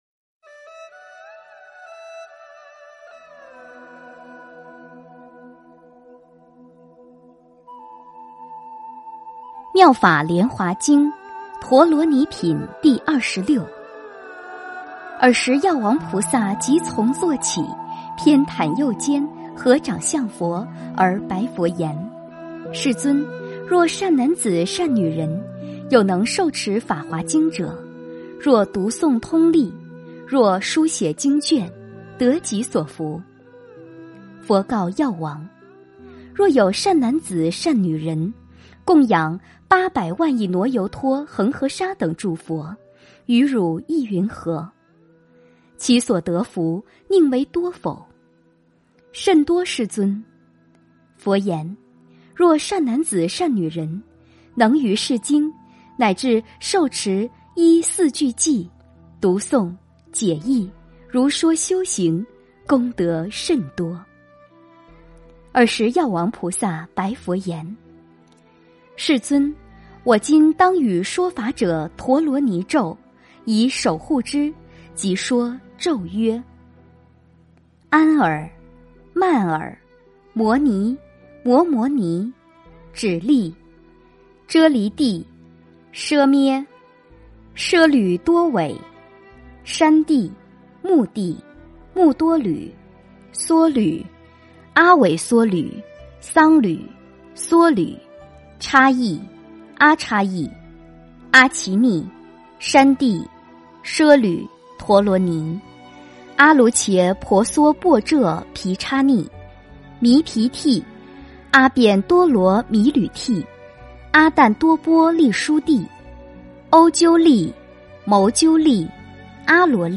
《妙法莲华经》陀罗尼品第二十六 - 诵经 - 云佛论坛